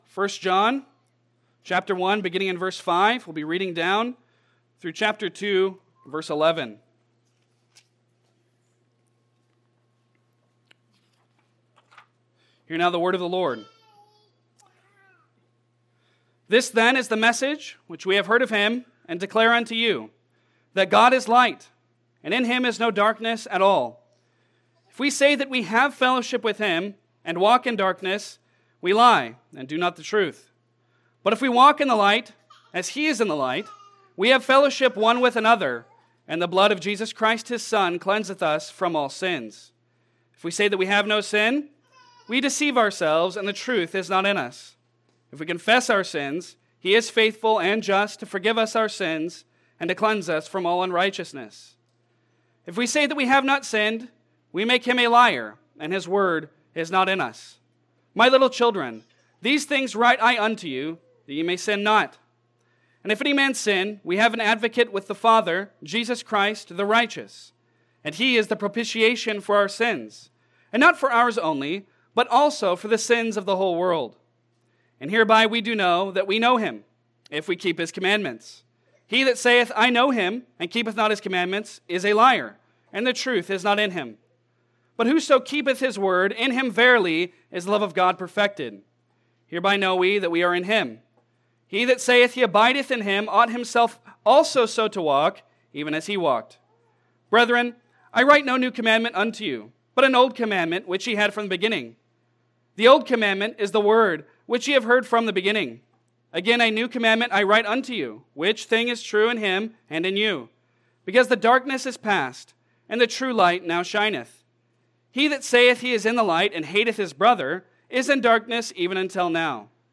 Passage: 1 John 1:5-2:11 Service Type: Sunday Sermon